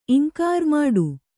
♪ imkār māḍu